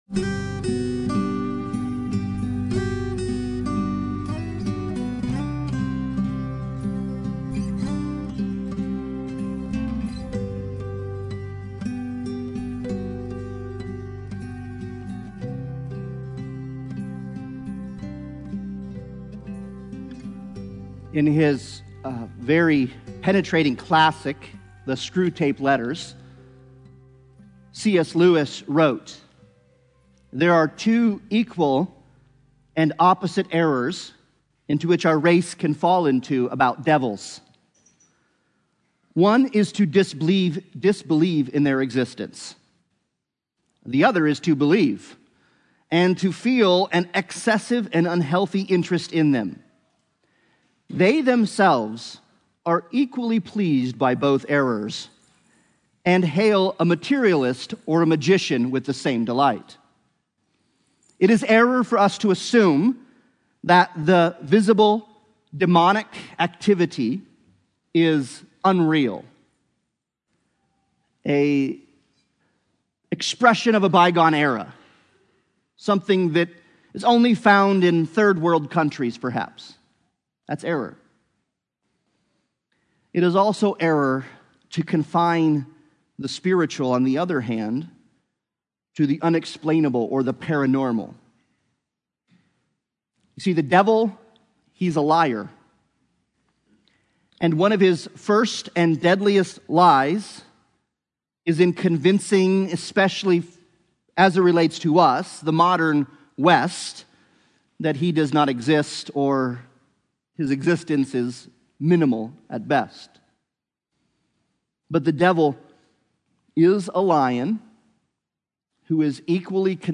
The Gospel According to Mark Service Type: Sunday Bible Study « Stand